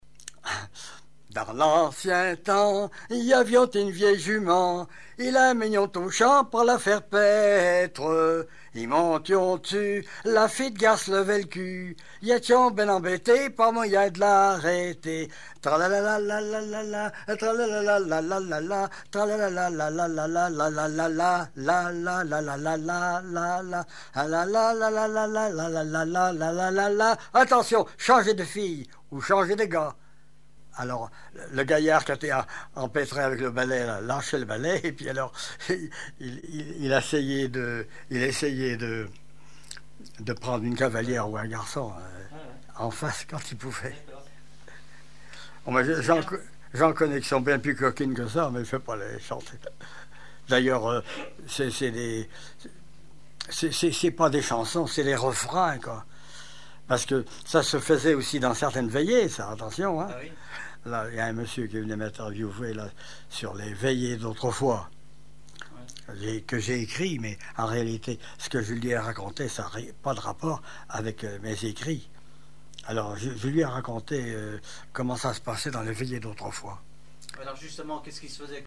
danse : ronde : boulangère ; danse-jeu : danse du balais ;
Pièce musicale inédite